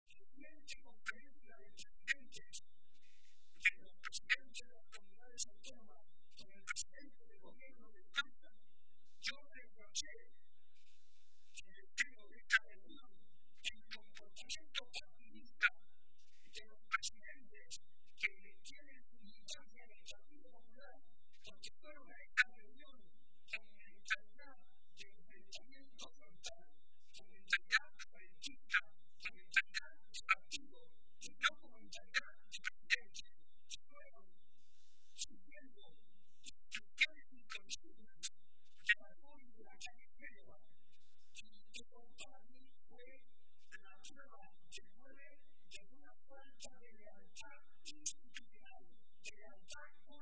Barreda realizó estas manifestaciones durante la celebración de la tradicional comida navideña del PSOE de Toledo, que ha tenido lugar hoy en la capital regional, y a la que también asistió la ministra de Sanidad y Política Social, Trinidad Jiménez.